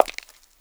PAVEMENT 4.WAV